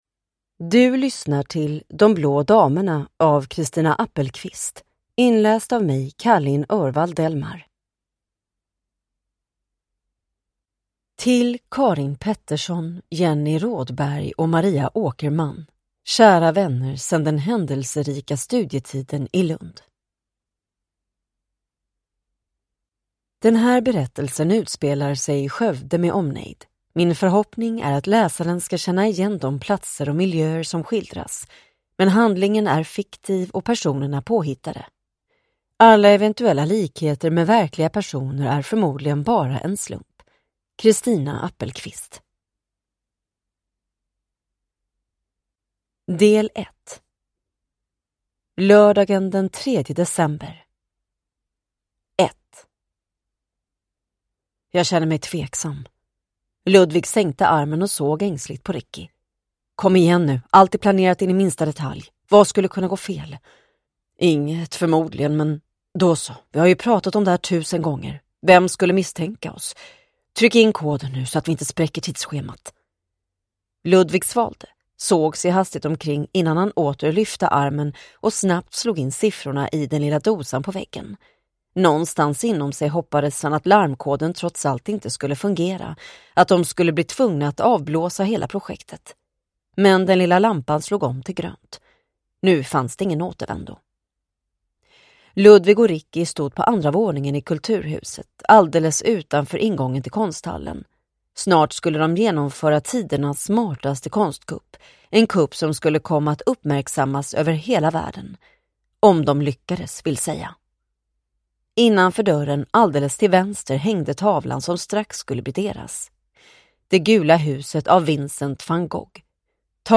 Ljudbok
Nyinspelad ljudbok med förbättrat ljud och populär inläsare!